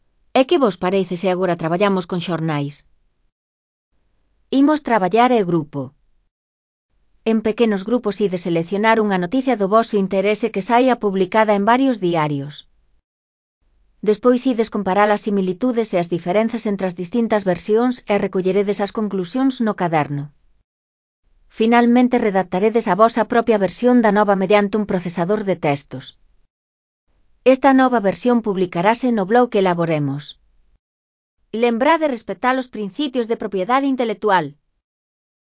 Elaboración propia (Proxecto cREAgal) con apoio de IA, voz sintética xerada co modelo Celtia. Compara e escribe (CC BY-NC-SA)